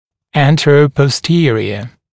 [ˌæntərəpɔs’tɪərɪə][ˌэнтэрэпос’тиэриэ]переднезадний